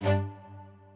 strings6_15.ogg